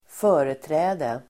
Uttal: [²f'ö:reträ:de]